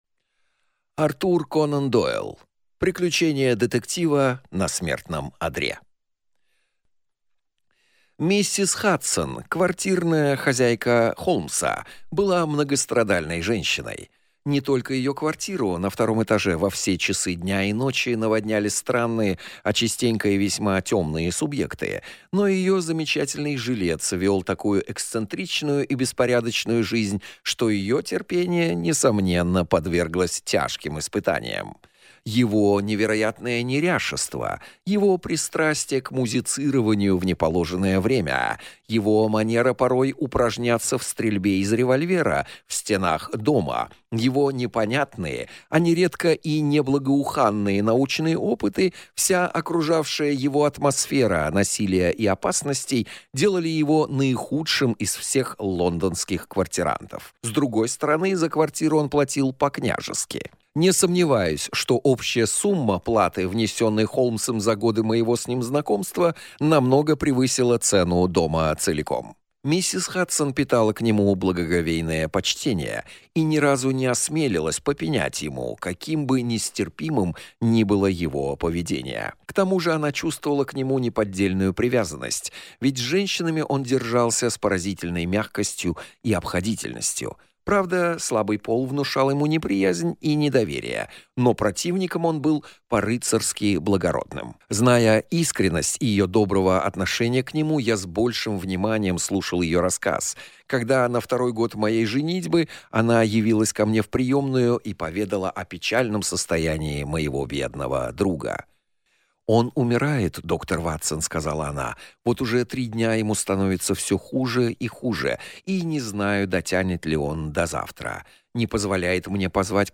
Аудиокнига Приключение детектива на смертном одре | Библиотека аудиокниг